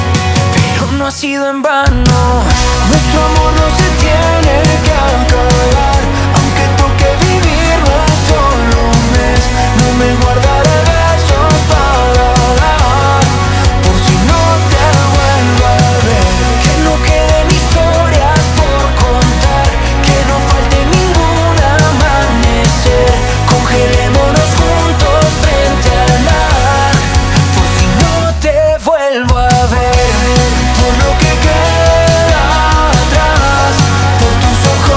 # Pop Latino